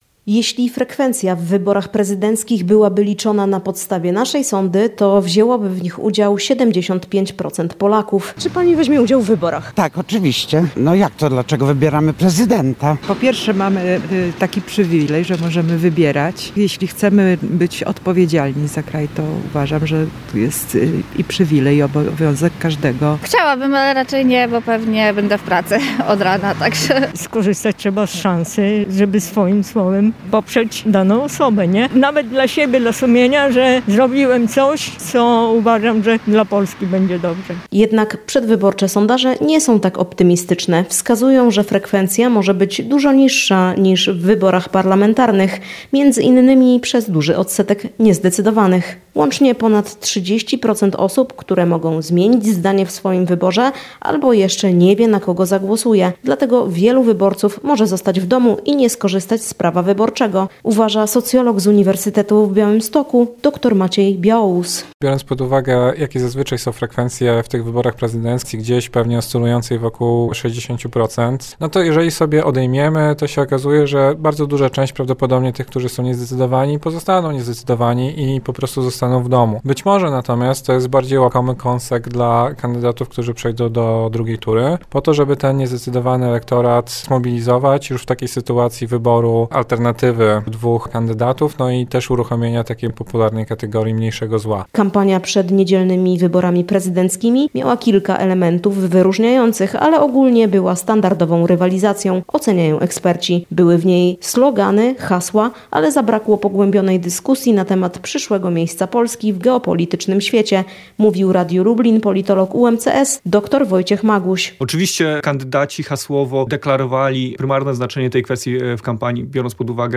Eksperci i mieszkańcy o kampanii wyborczej